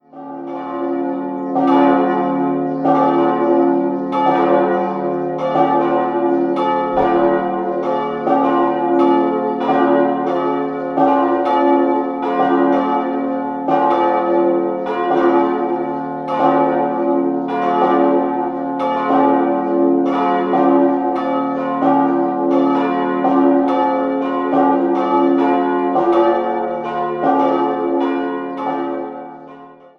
Des-Dur-Dreiklang: des'-f'-as' Die Eisenhartgussglocken wurden 1922 von Schilling&Lattermann gegossen.